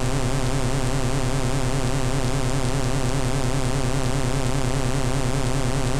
Index of /90_sSampleCDs/Trance_Explosion_Vol1/Instrument Multi-samples/Scary Synth
C1_scary_synth.wav